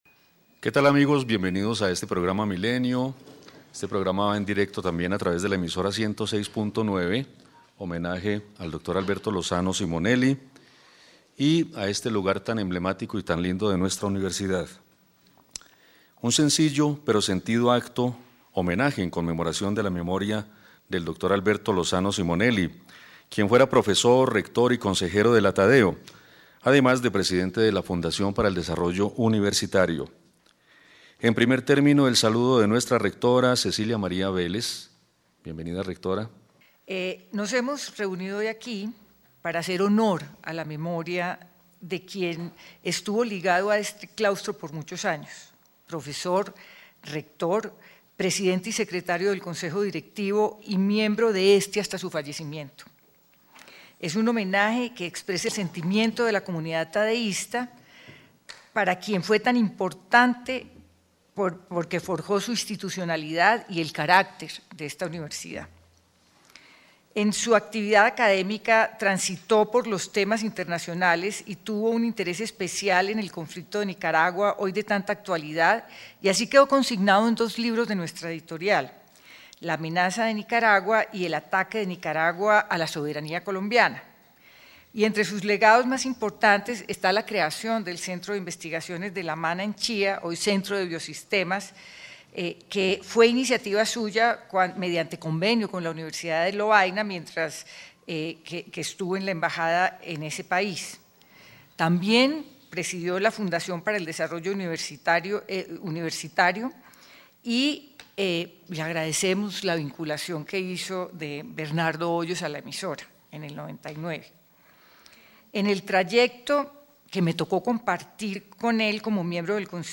A través de un sentido y conmovedor conversatorio, amigos y familiares recordaron a quien fuera rector de la Universidad Jorge Tadeo Lozano, y parte de su Consejo Directivo.
El pasado martes 16 de febrero en el Hemiciclo de Utadeo, se llevó a cabo la conmemoración del primer aniversario del fallecimiento del doctor Alberto Lozano Simonelli, quien fue rector de la Universidad Jorge Tadeo Lozano, e hizo parte del Consejo Directivo de la Universidad.